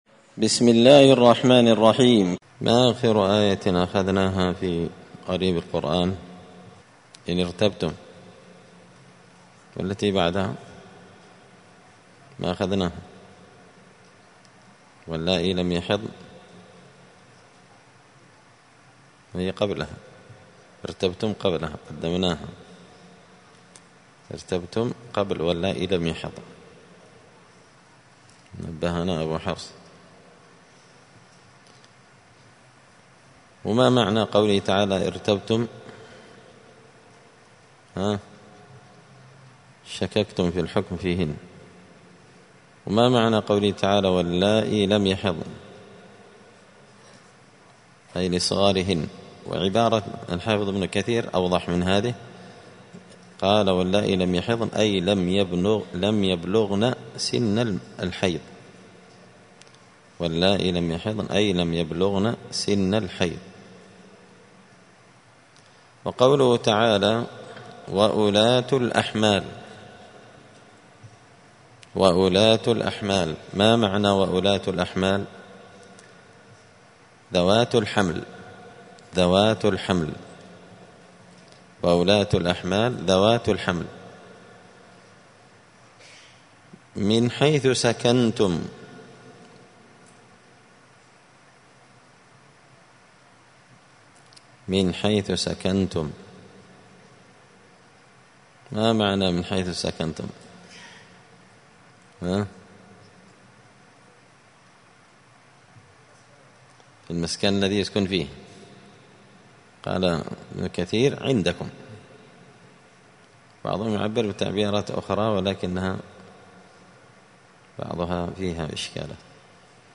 الثلاثاء 2 رمضان 1445 هــــ | الدروس، دروس القران وعلومة، زبدة الأقوال في غريب كلام المتعال | شارك بتعليقك | 32 المشاهدات
دار الحديث السلفية بمسجد الفرقان بقشن المهرة اليمن